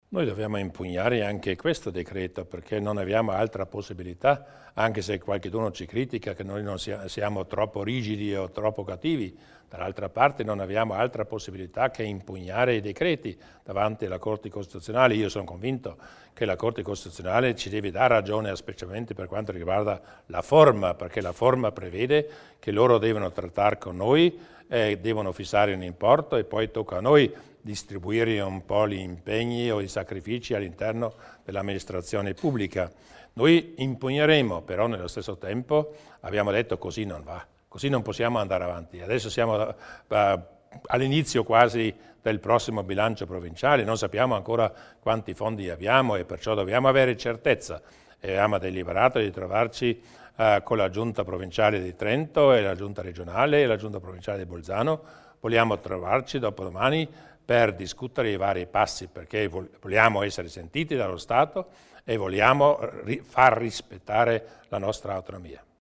Il Presidente Durnwalder spiega i prossimi passi dopo i tagli imposti dal Governo Monti